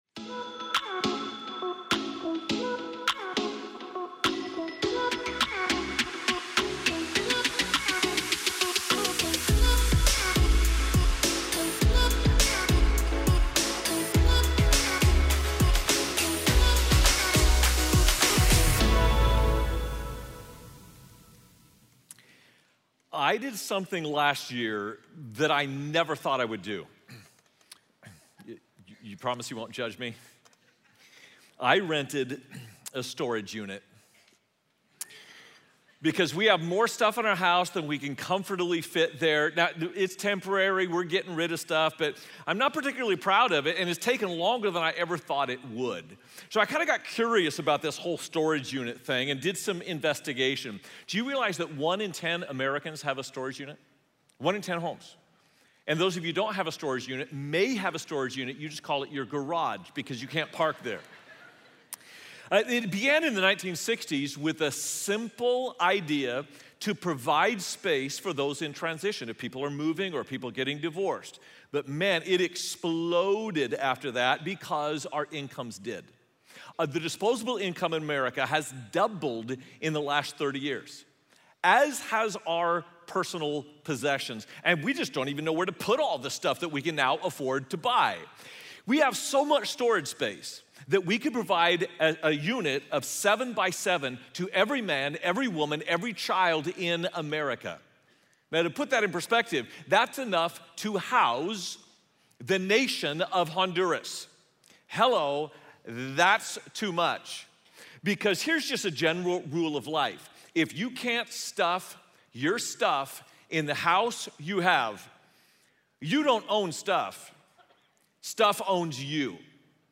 Missed church service this weekend or want to hear the message again?